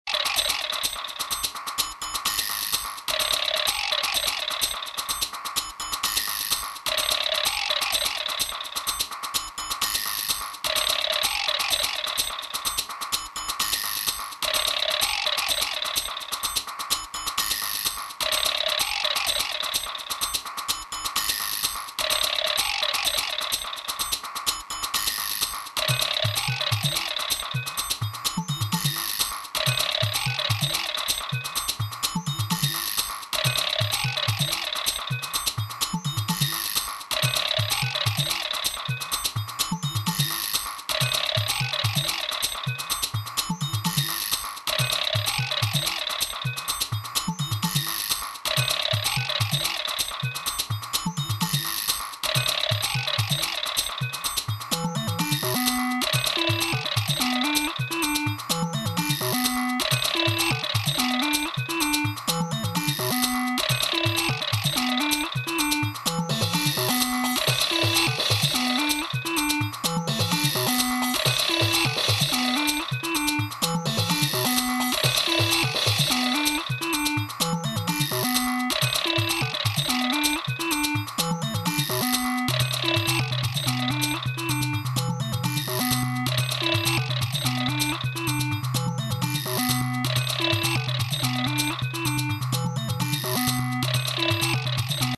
studio sessions from 1996-2009
original electronic cult past favorites
Electronix House